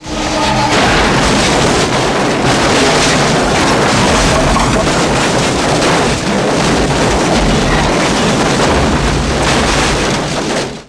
Carcrash.wav